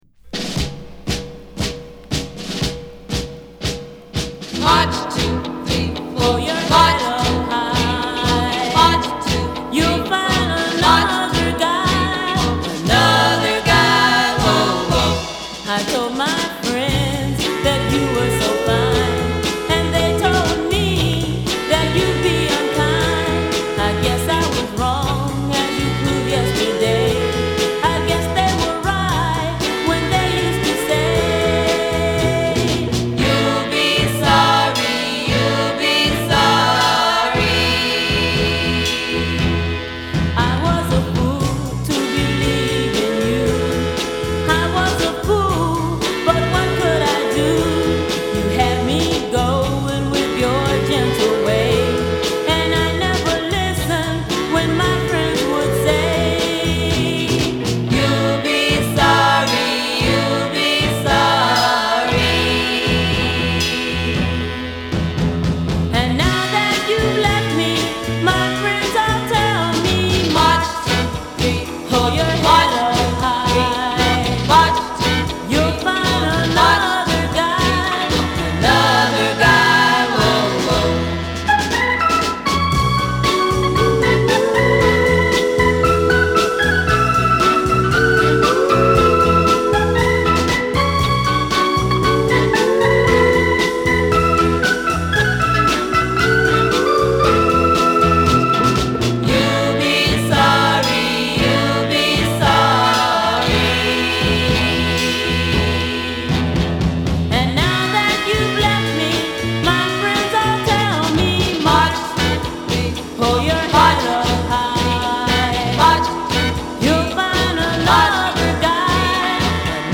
ブロンクス出身のガール・グループ。